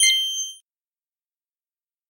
Звук открытого доступа в интерфейсе пользователя